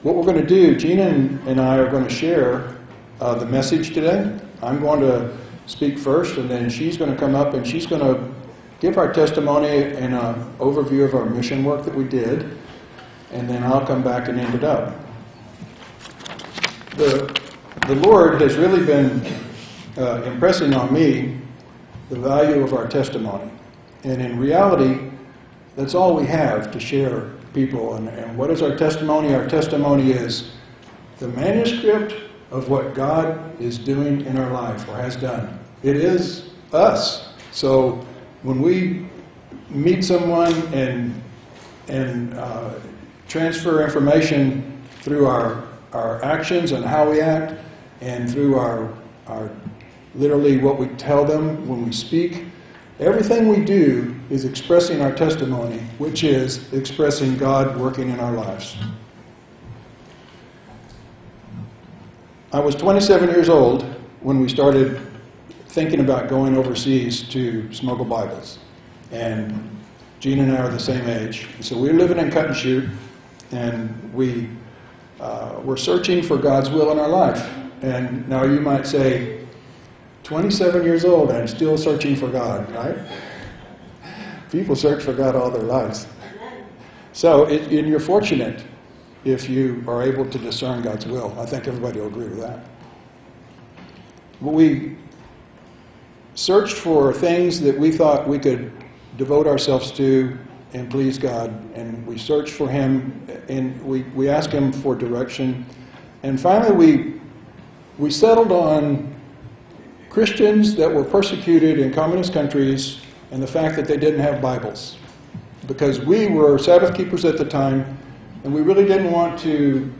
9-9-17 sermon